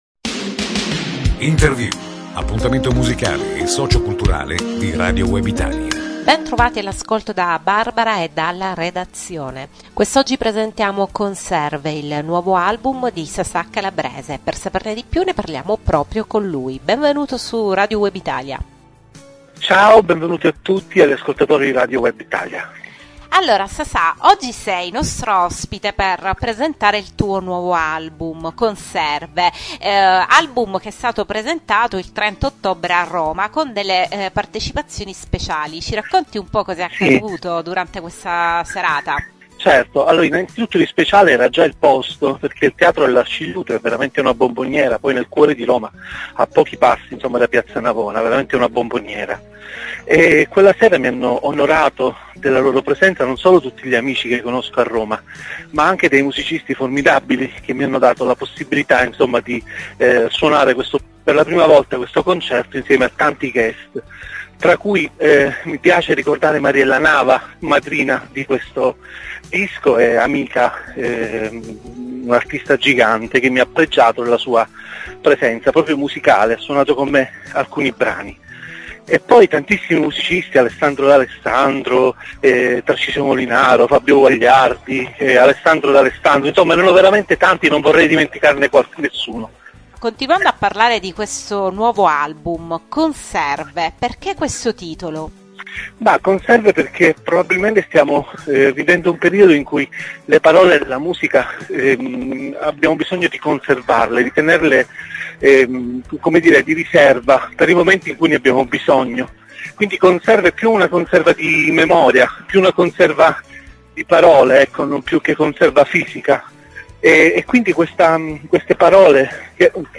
Intervista On Air venerdì 23 novembre ore 18.10